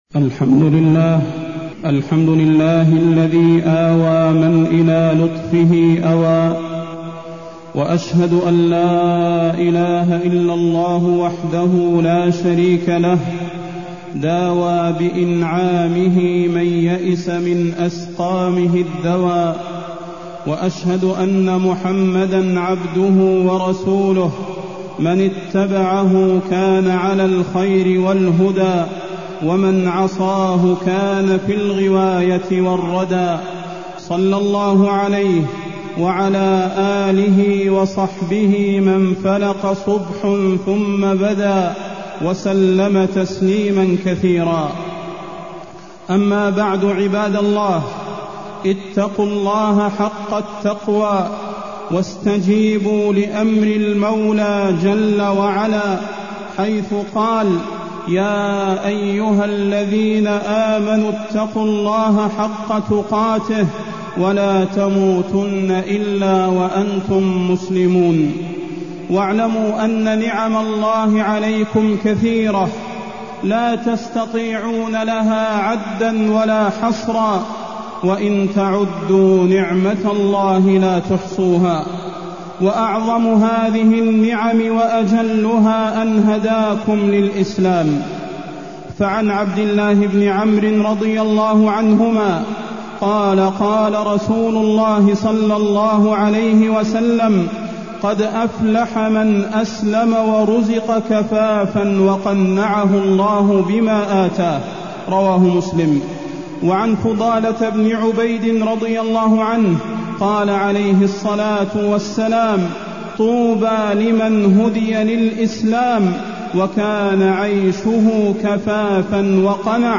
تاريخ النشر ١٤ شوال ١٤٢٠ المكان: المسجد النبوي الشيخ: فضيلة الشيخ د. صلاح بن محمد البدير فضيلة الشيخ د. صلاح بن محمد البدير الفتن سنة ربانية